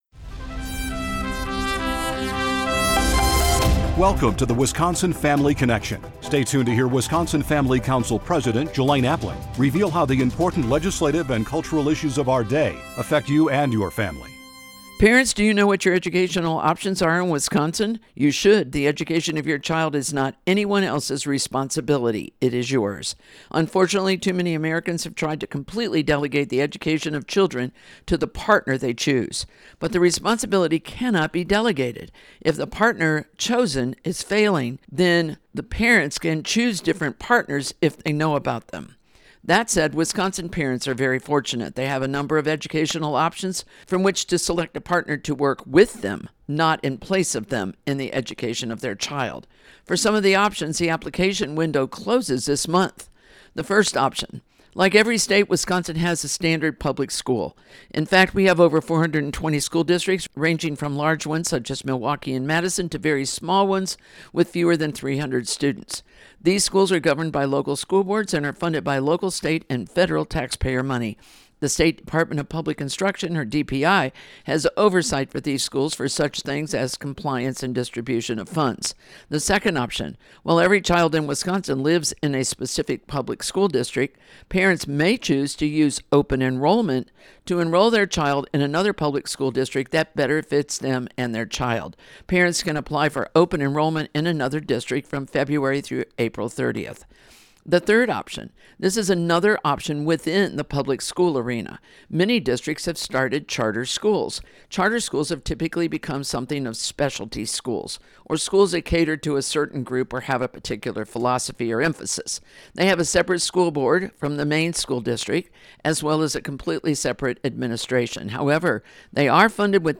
2024 | Week of April 8 | Radio Transcript #1561